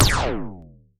poly_explosion_small4.wav